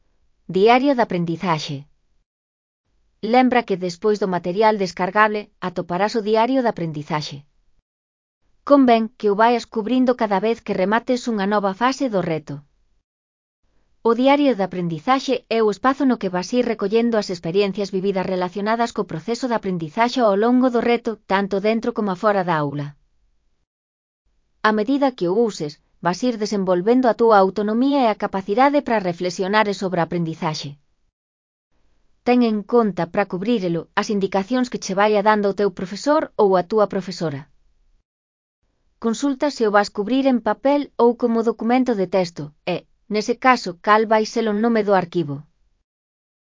Elaboración propia (Proxecto cREAgal) con apoio de IA, voz sintética xerada co modelo Celtia. Diario de aprendizaxe (CC BY-NC-SA)